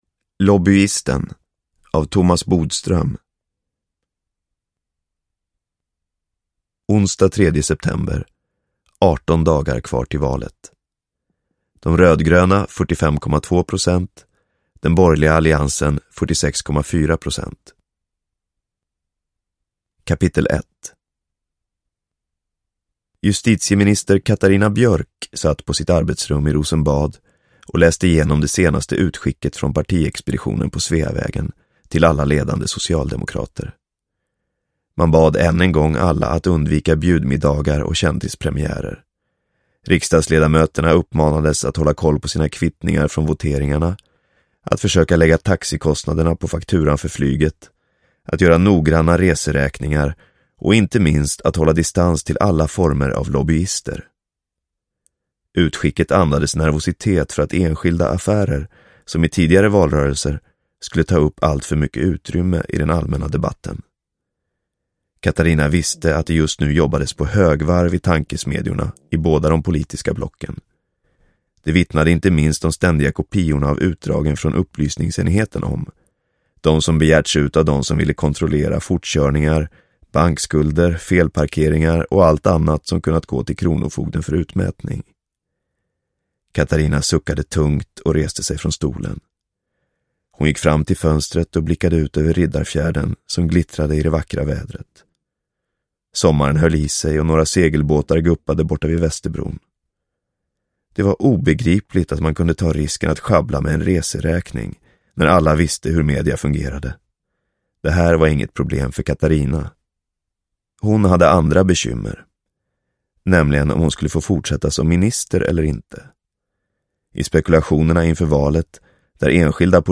Lobbyisten – Ljudbok – Laddas ner
Uppläsare: Ola Rapace